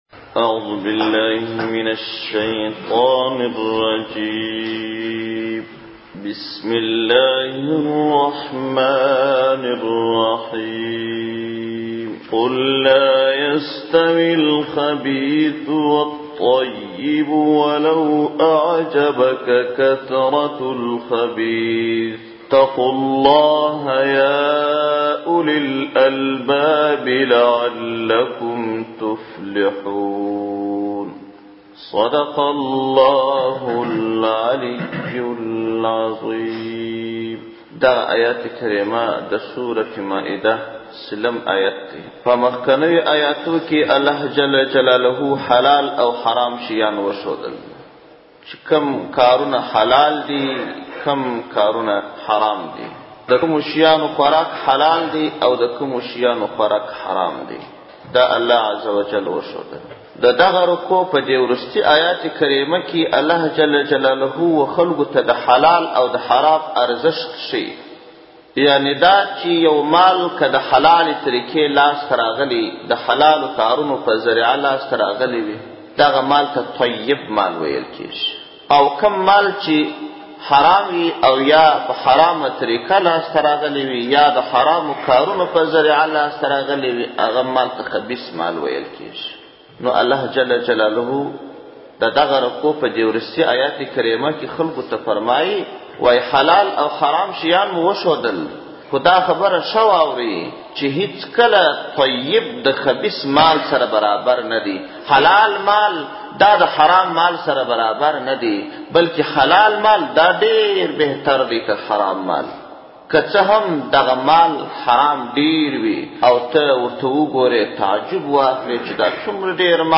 دسمبر 4, 2016 تفسیرشریف, ږغیز تفسیر شریف 1,061 لیدنی